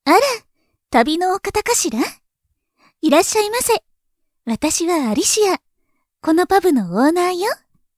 性別：女